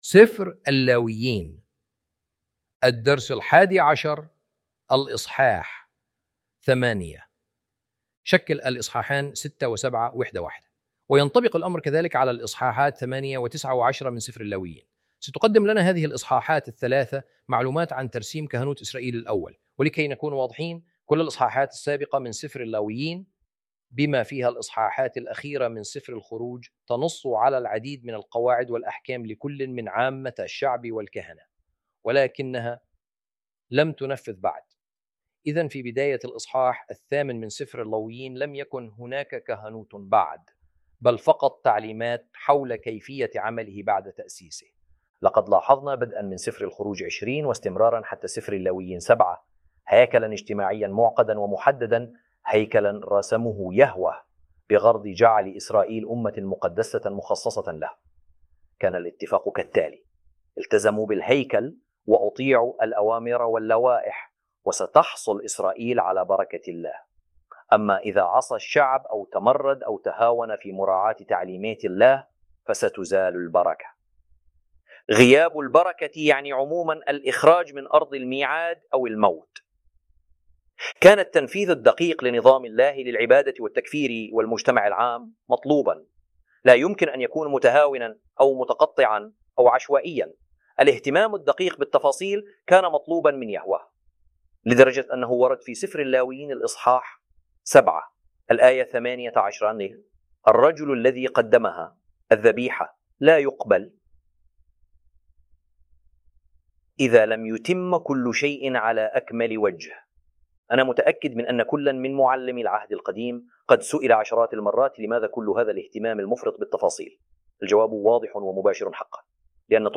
الدرس الحادي عشر – الإصحاح ثمانية